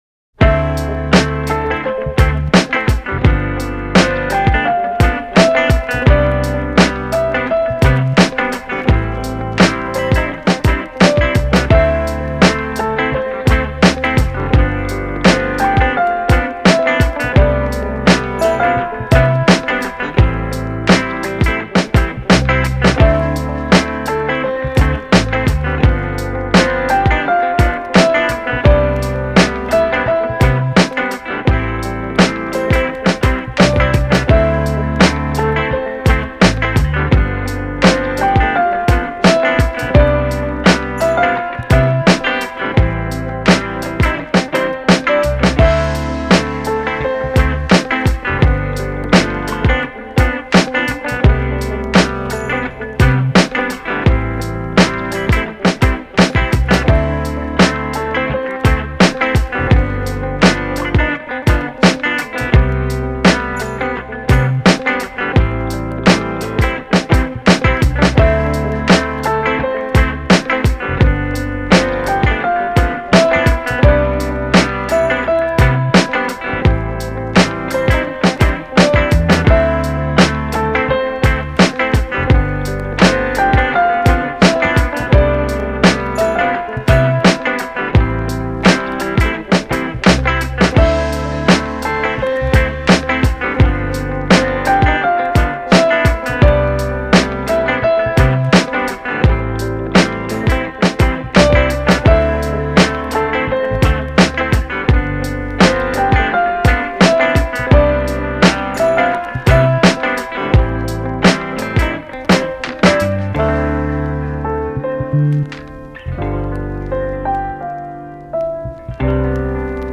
カフェミュージック チル・穏やか フリーBGM メロウ・切ない